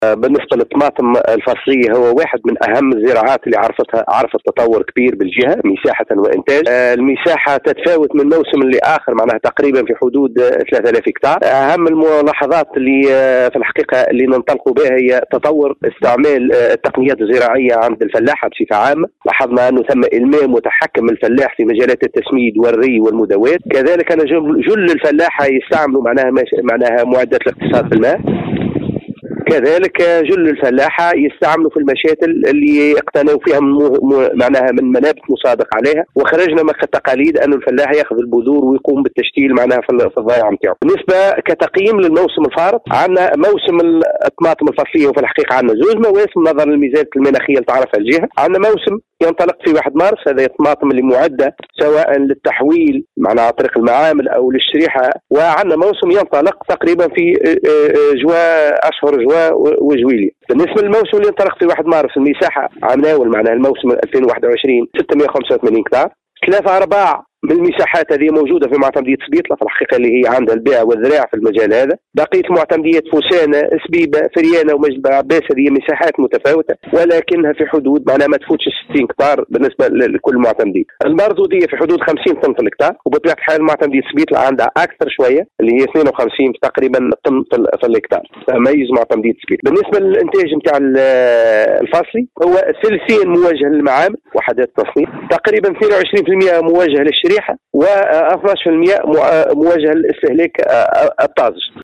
القصرين: تطور تقنيات و وسائل إنتاج الطماطم الفصلية التي تتميز بانتاجها الجهة (تصريح)